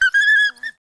Sound / sound / monster / wolf / fall_1.wav
fall_1.wav